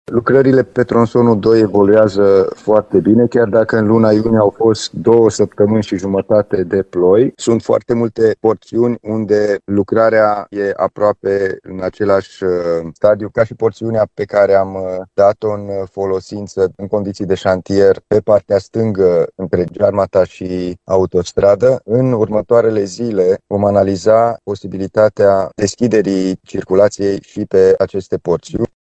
În ceea ce privește tronsonul al doilea al drumului, constructorul se află în graficul de execuție a lucrărilor, mai spune Alin Nica.